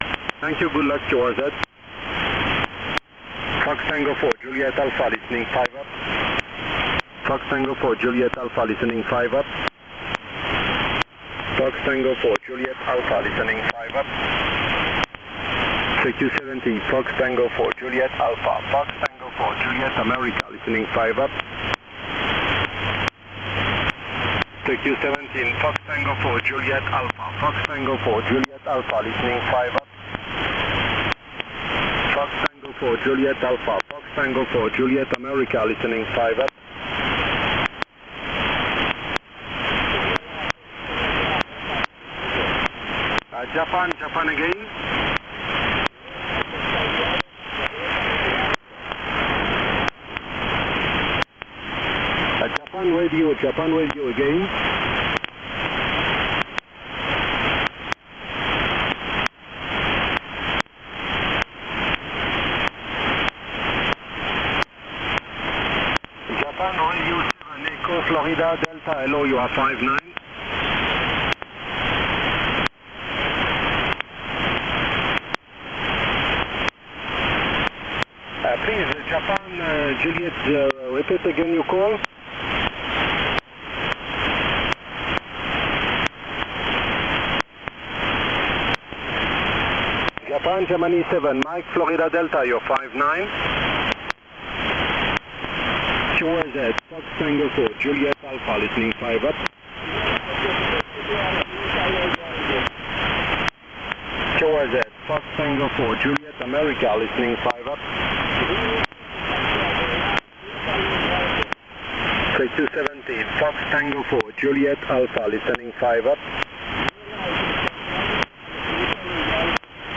FT4JA -Juan de Nova on 18MHz SSB